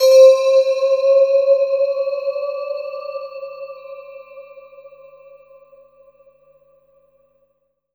12SYNT01  -R.wav